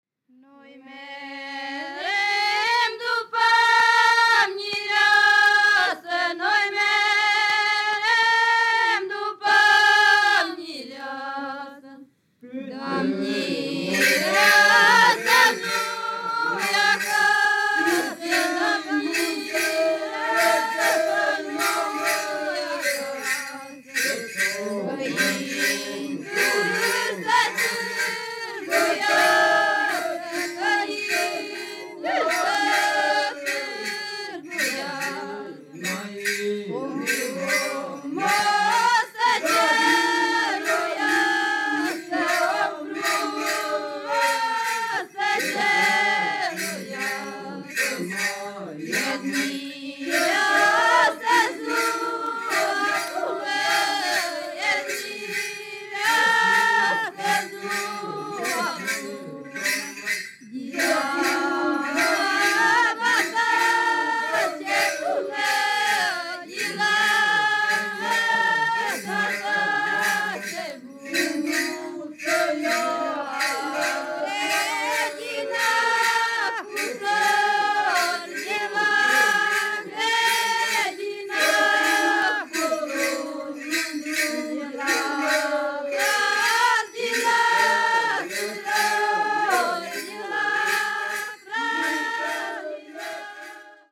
フランス・OCORAからルーマニア民族音楽です。